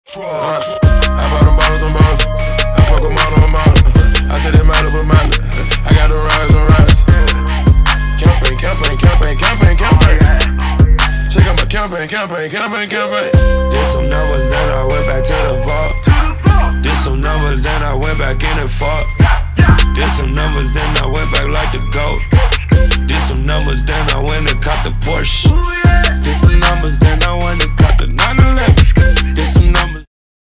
• Качество: 320, Stereo
мужской вокал
рэп
dance
спокойные
качающие
Bass